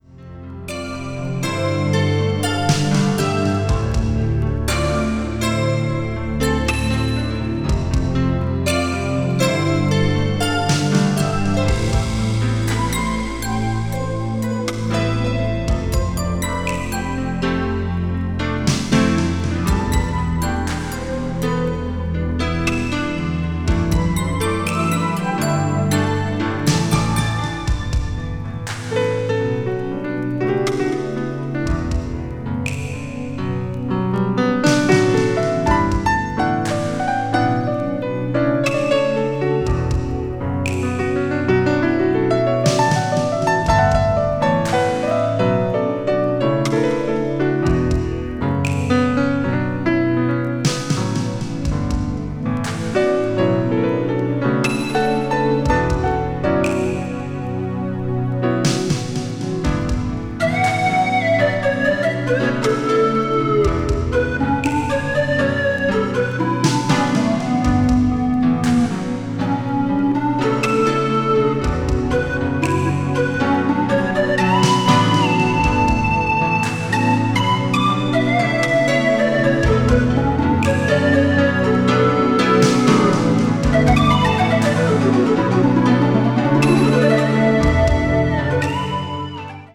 crossover   electronic   fusion   new age jazz   synthesizer